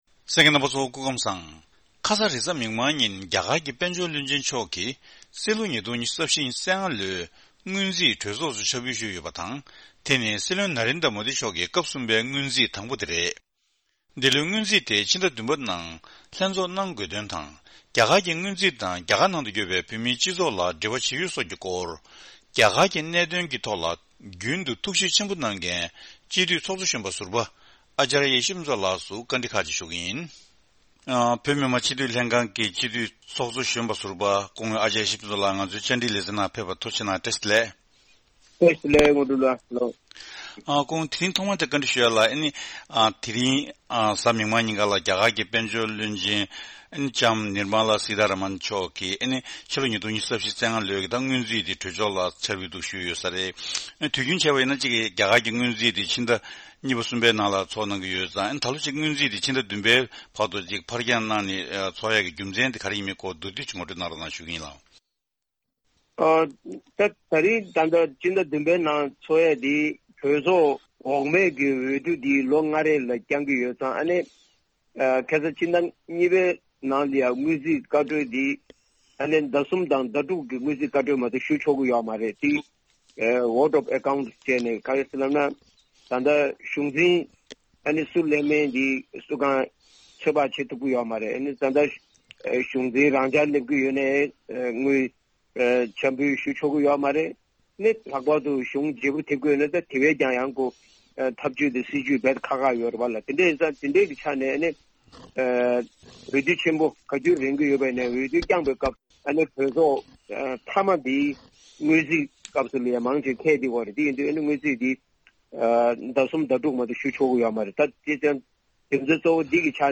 དེ་རིང་གི་བཅར་འདྲིའི་ལེ་ཚན་ནང་།